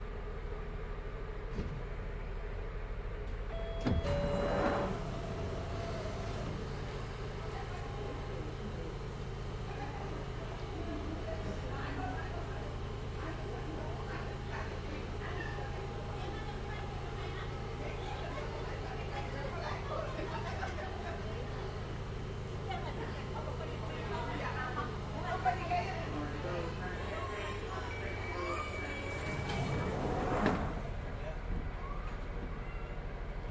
伦敦地铁 " 地铁列车开放和关闭
描述：从管列车内部记录，打开然后不久，关闭。记录来自瑞士小屋 使用手持式录像机以立体声录制
Tag: 开放 伦敦 地铁 车站 地铁 伦敦地铁 火车 山寨 停止 开放 瑞士